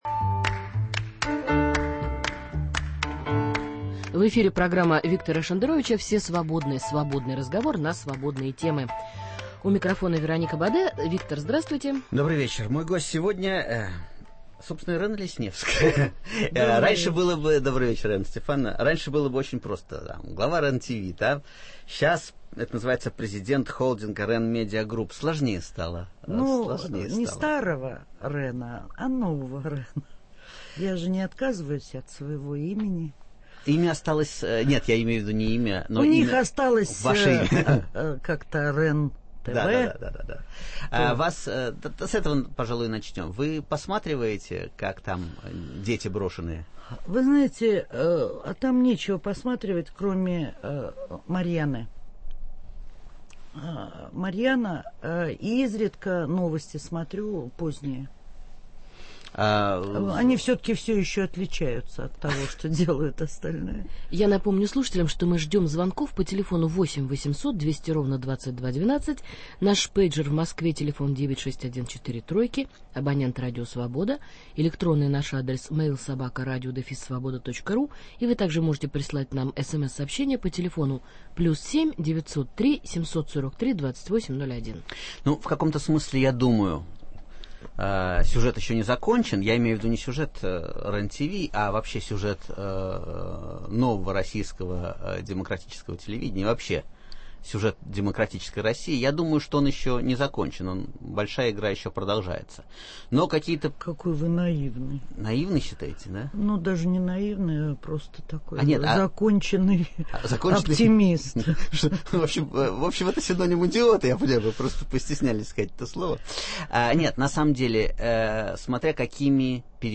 В гостях у Виктора Шендеровича - член академии Российского телевидения, президент холдинга РЕН-МЕДИА-ГРУПП Ирена Лесневская.